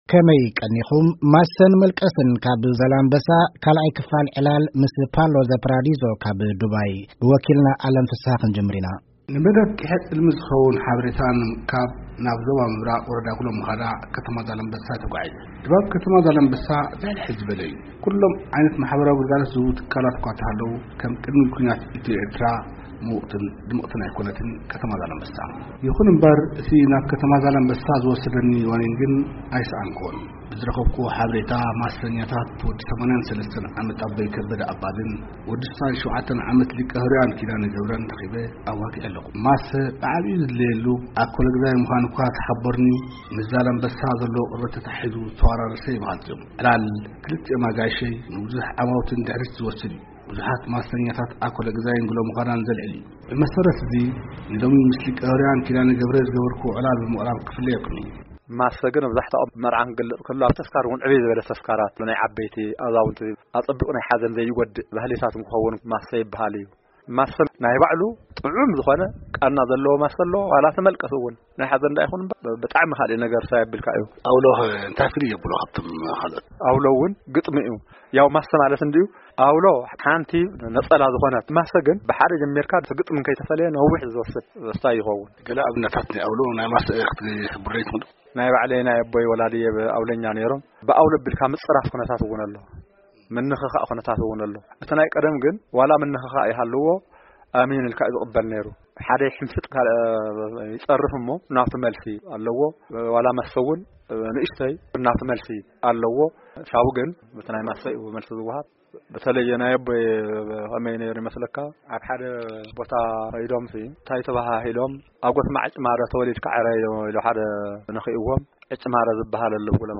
ቂሔ ጽልሚ: ዕላል ምስ ማሰኛታት ዛላምበሳ